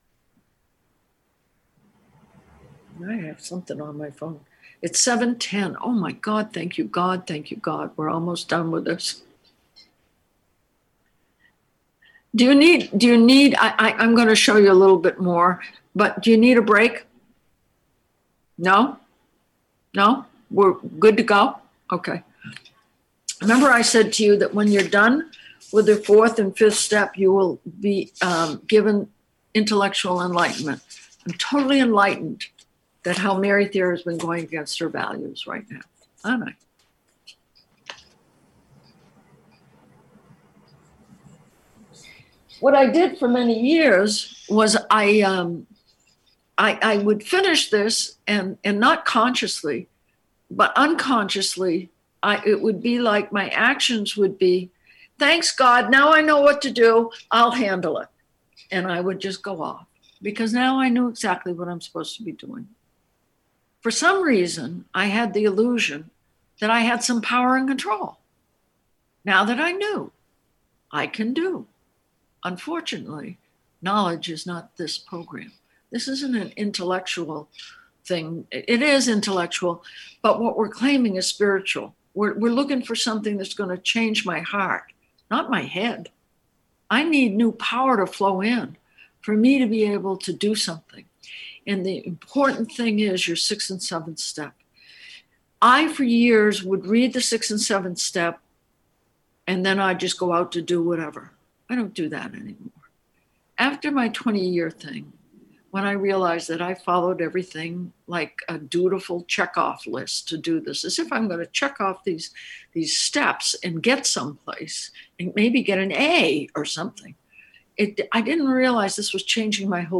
Emotional Sobriety - AWB Workshop